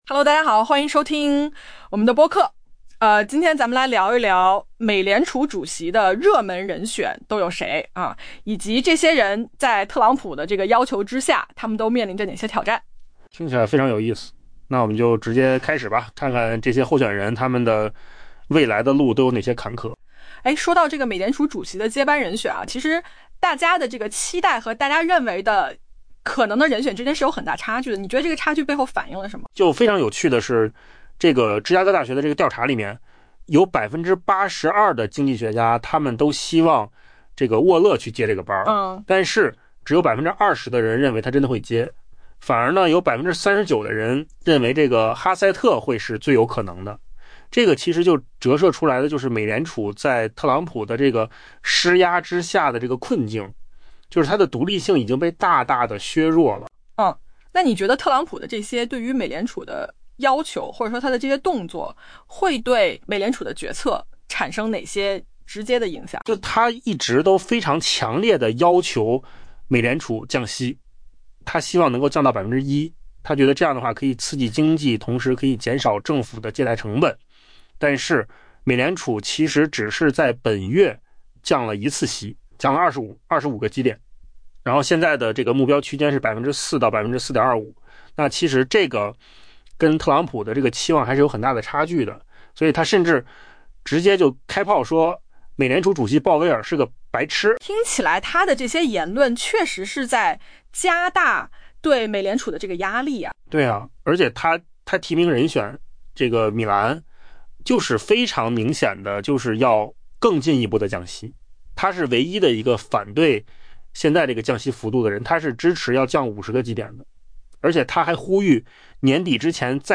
AI 播客：换个方式听新闻 下载 mp3 音频由扣子空间生成 学院派经济学家们压倒性地希望美联储理事沃勒能接替鲍威尔，明年担任美联储主席，但很少有人认为他会得到这份工作。